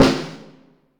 Snare (French!).wav